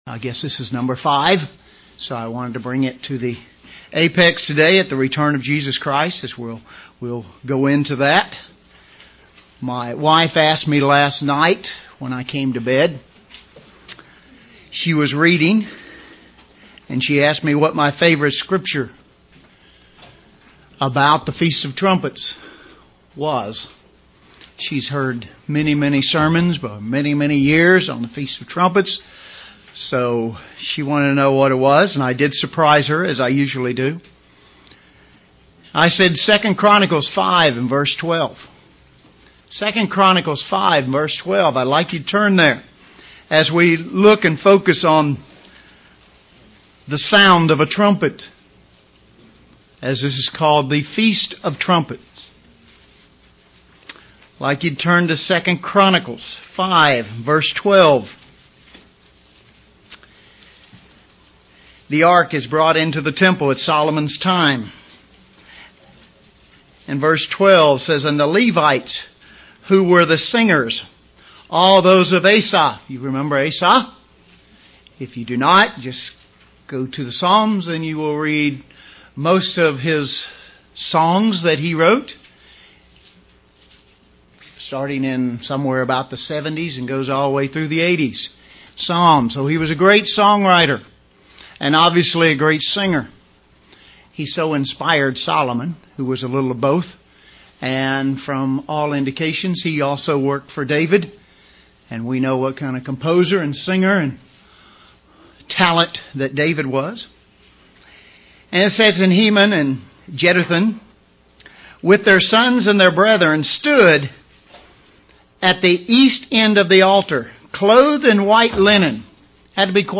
The seven seals described in the book of Revelation UCG Sermon Transcript This transcript was generated by AI and may contain errors.